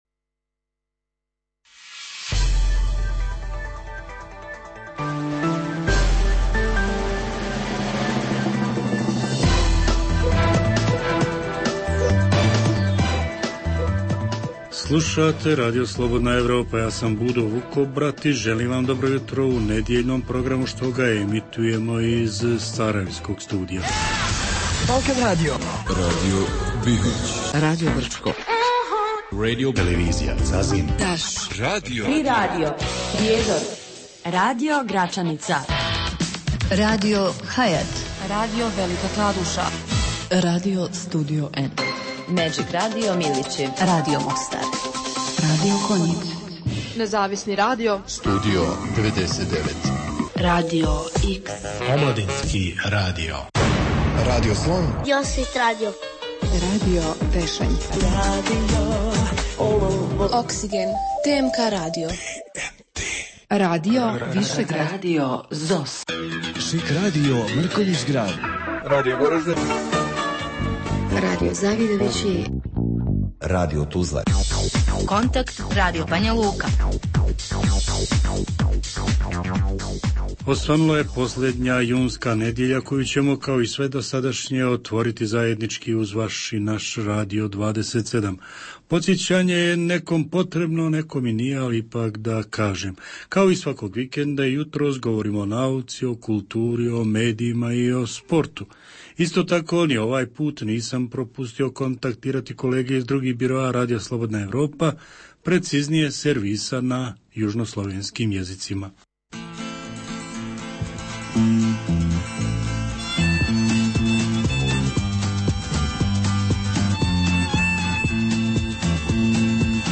Jutarnji program namijenjen slušaocima u Bosni i Hercegovini.